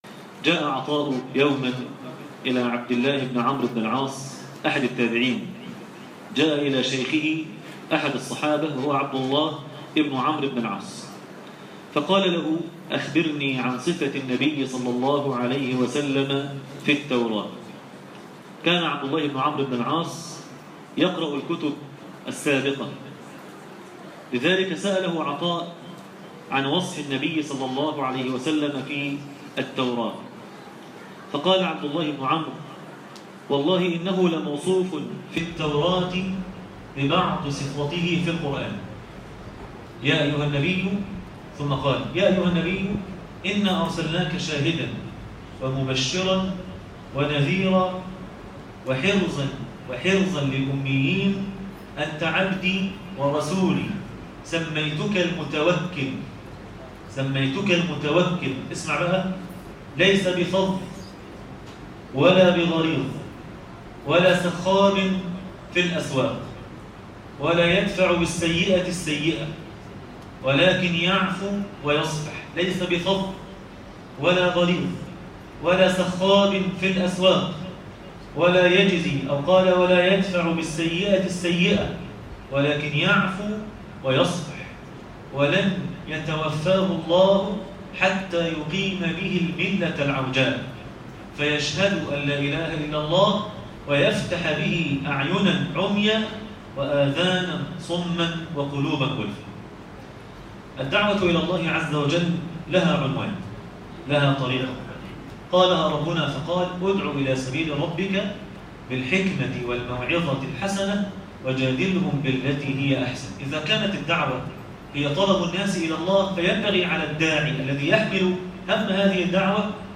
النبي (صلي الله عليه وسلم ) داعيا - الجزء الثالث- درس التراويح ليلة 8 رمضان 1437هـ